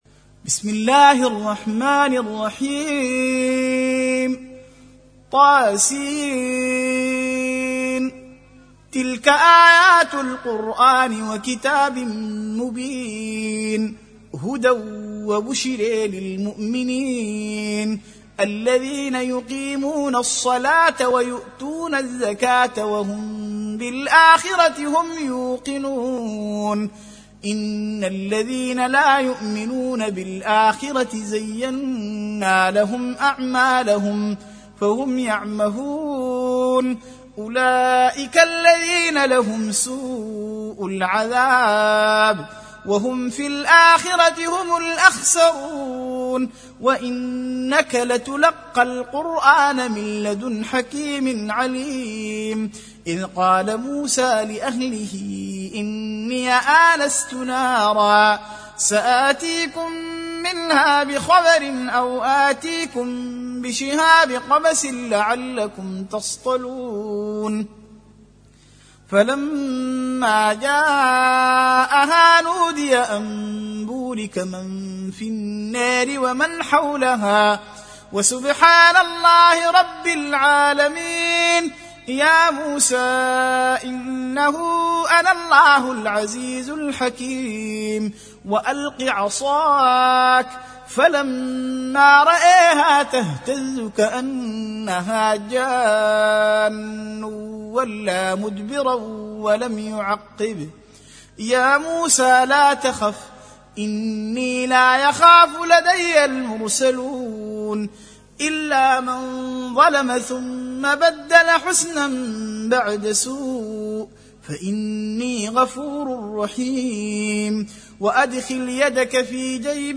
Surah Repeating تكرار السورة Download Surah حمّل السورة Reciting Murattalah Audio for 27. Surah An-Naml سورة النّمل N.B *Surah Includes Al-Basmalah Reciters Sequents تتابع التلاوات Reciters Repeats تكرار التلاوات